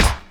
Snares
snare - Encore.wav